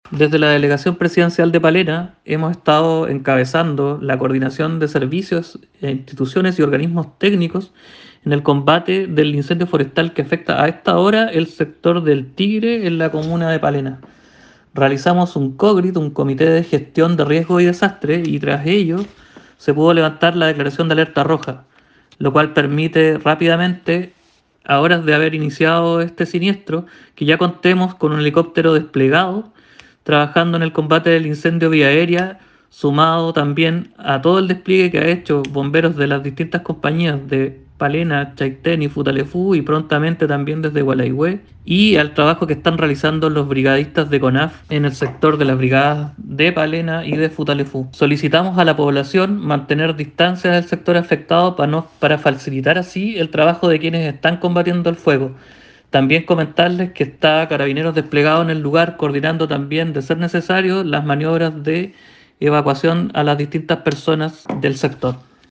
A su vez, el delegado de Palena Luis Montaña manifestó que se está coordinando a todos los servicios necesarios para poder terminar con esta emergencia.